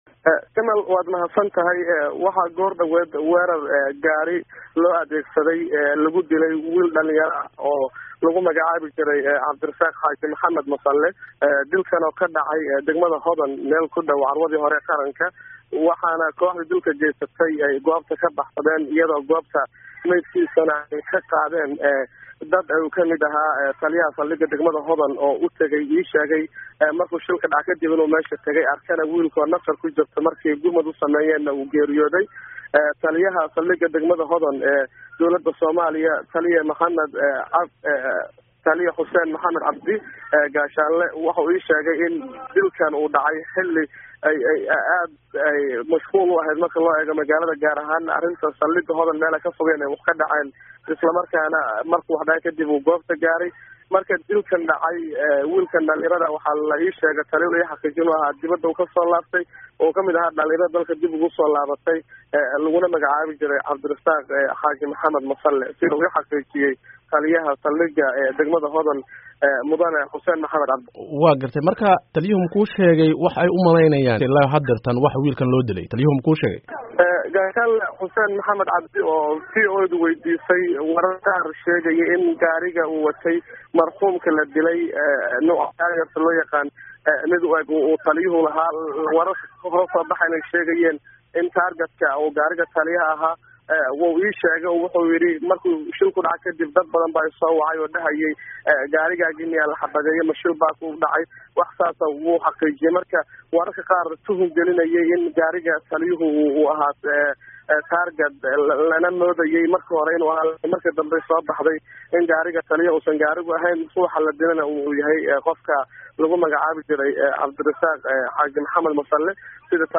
ka wareystay